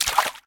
Footsteps_Water_4.ogg